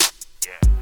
JJSnares (39).wav